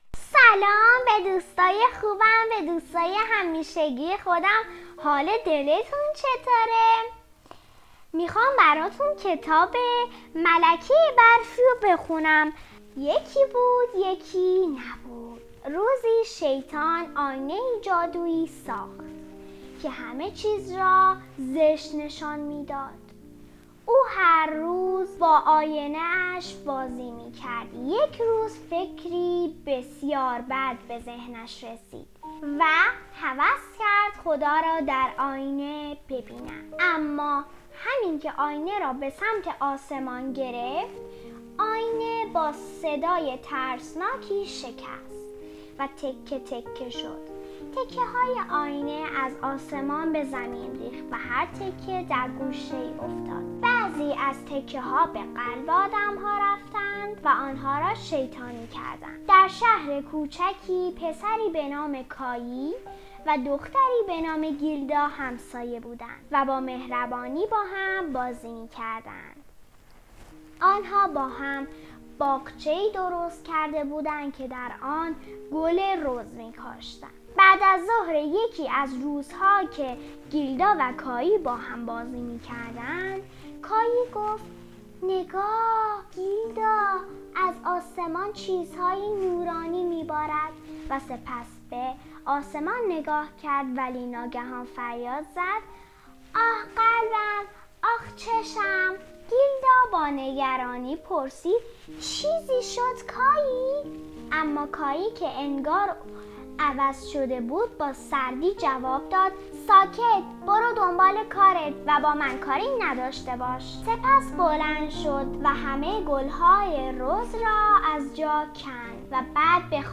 قصه صوتی